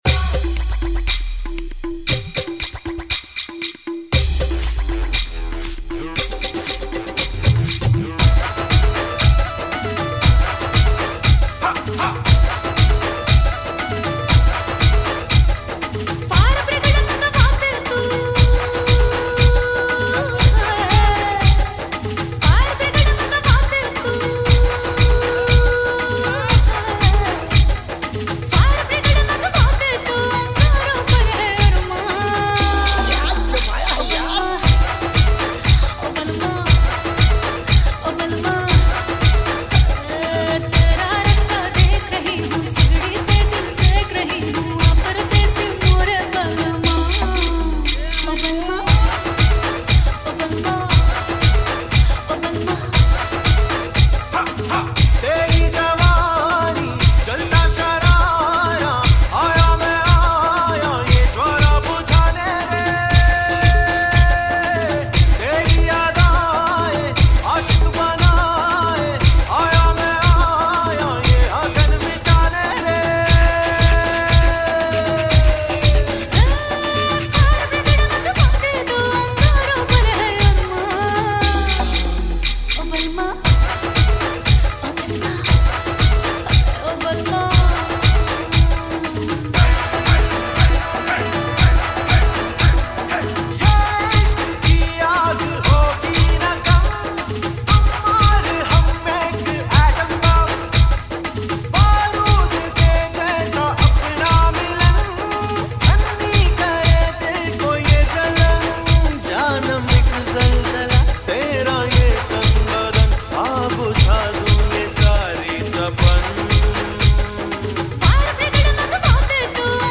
Gujarati Songs And Dandiya